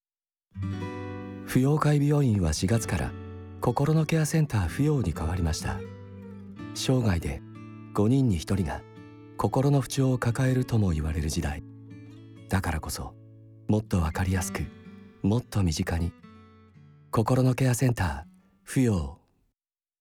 「こころのケアセンターふよう」音声CM